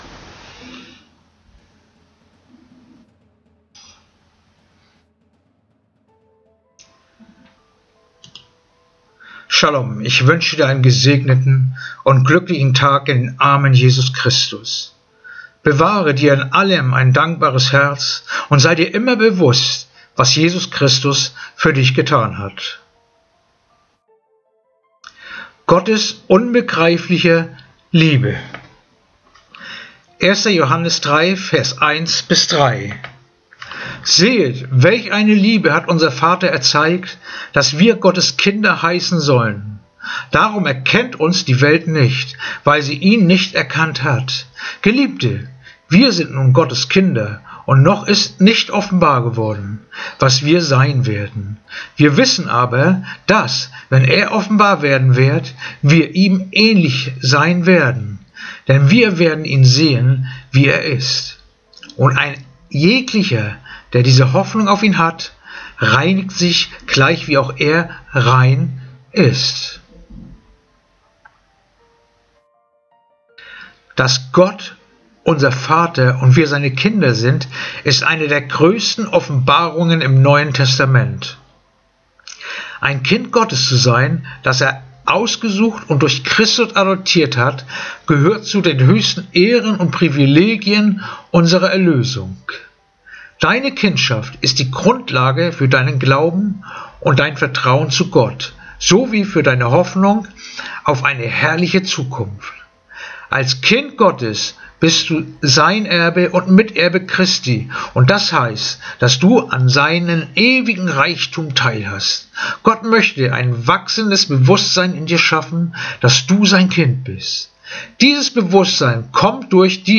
Andacht-vom-23.-März-1.Johannes-31-3